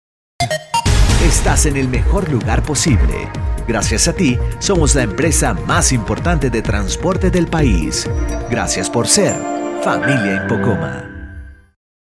Sprechprobe: Industrie (Muttersprache):
Male Spanish voice over, latin voice, young voice, fresh voice, Español, voiceover, locutor, voice acting, dubbing actor, video corporativo, voz masculina, acento neutro, acento venezolano, warm, comforting, powerful, sincere, authentic, fun, relaxed, conversational, GENUINE, FRIENDLY
JINGLE 4 verision 1_3.MP3